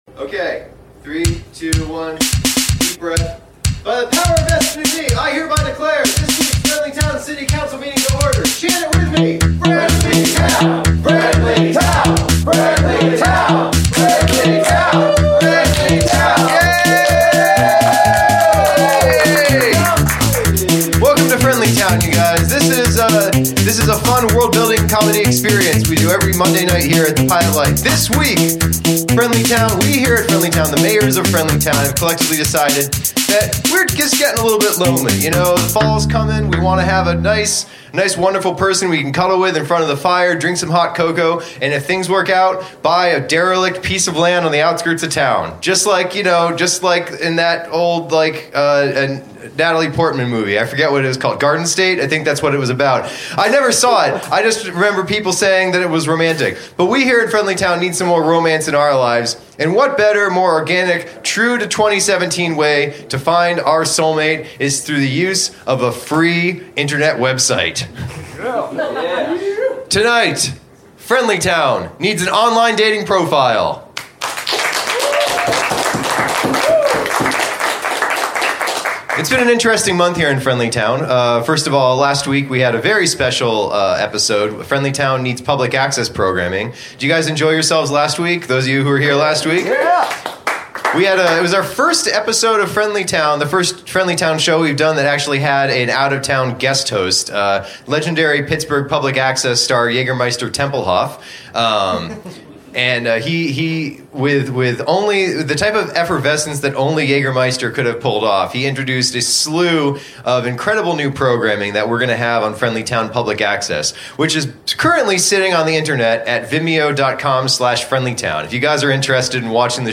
Recorded Live at the Pilot Light September 18, 2017, Knoxville TN